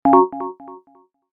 Short Electronic Notification Alert Sound Effect
Description: Short electronic notification alert sound effect. A clean, high-frequency electronic notification sound delivers clear user interface feedback. It features a sharp percussive attack with a fast decay for instant recognition.
Short-electronic-notification-alert.mp3